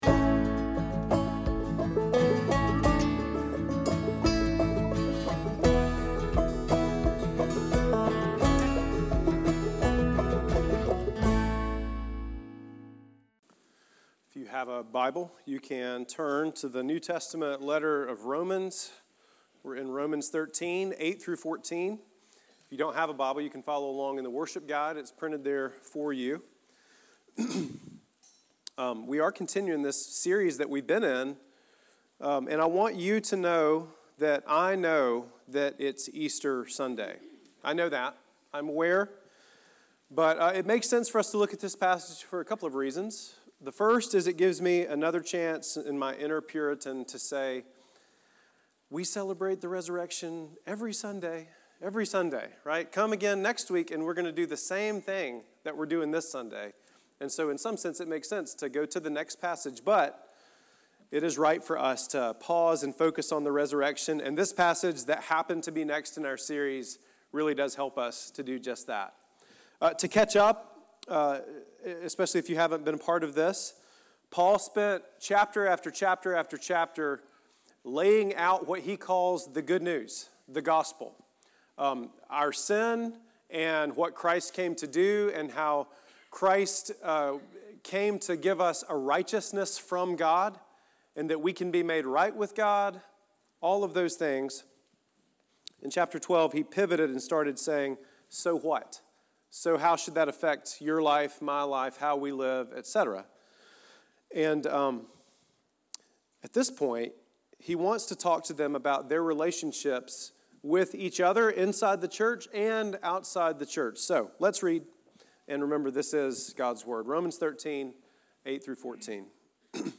Easter-Sunday-2026.mp3